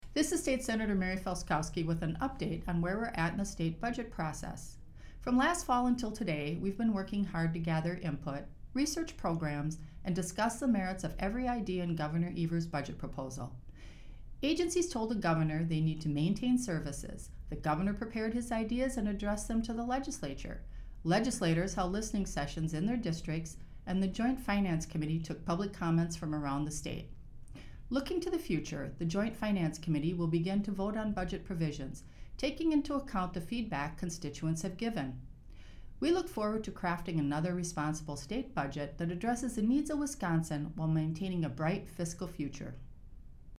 Weekly GOP radio address: Sen. Felzkowski talks about crafting a responsible budget - WisPolitics